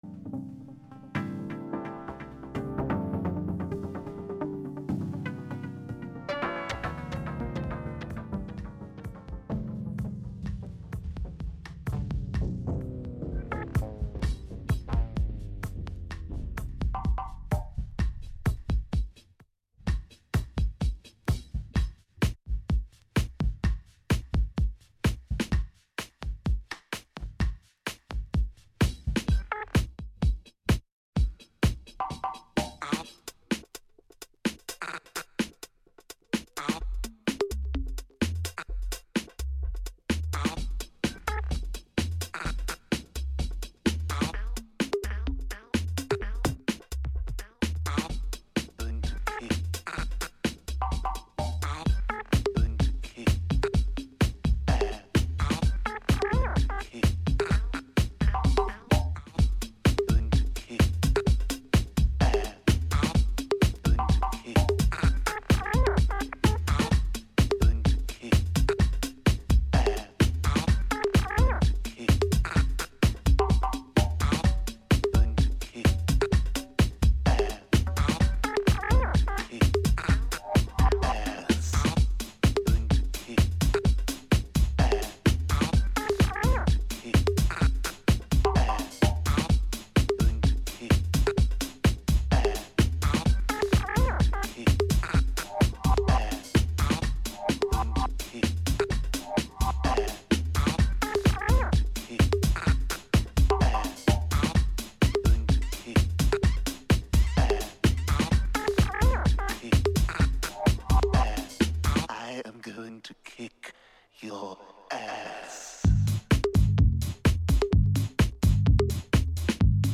Minimal House Techno Afterhours Mix (Direct Download)
FILE UNDER: Microhouse, Minimal House, Minimal Techno
Afterhour tag sessions from 2006, playing ones, or twos amongst a handful of DJs.